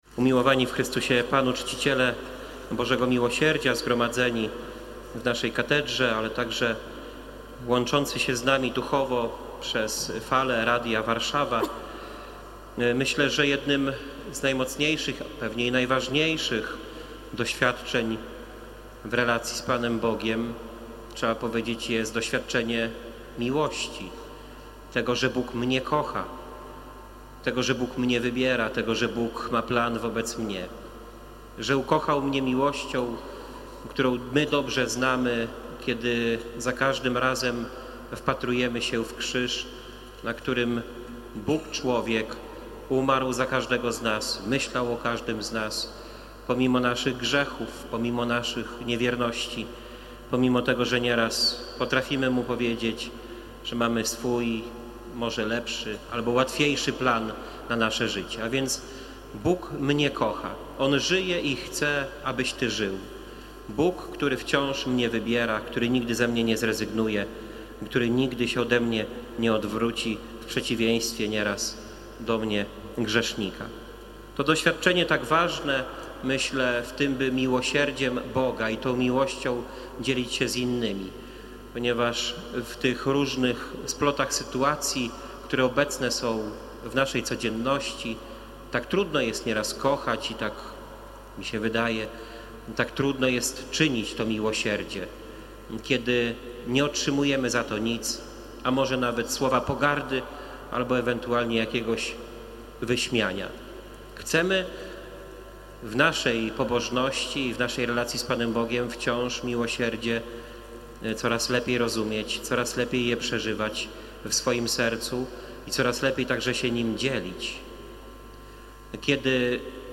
cała-homilia.mp3